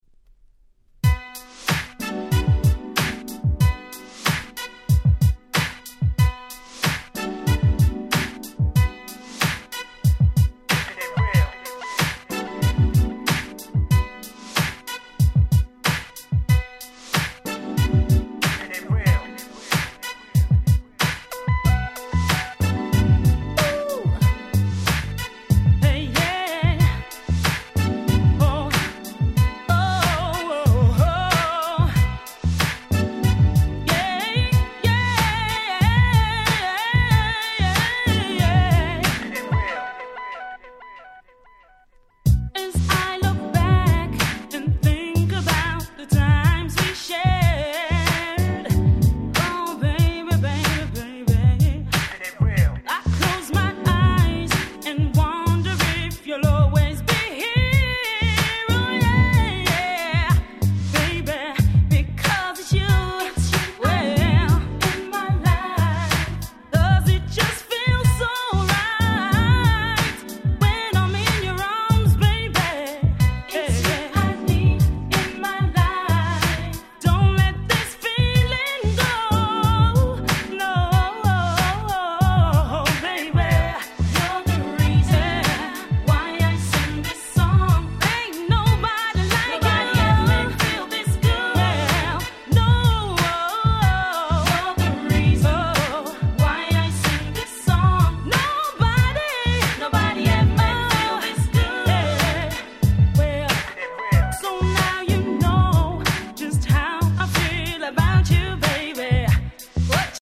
99' Nice UK R&B !!